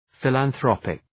Προφορά
{,fılən’ɵrɒpık}
philanthropic.mp3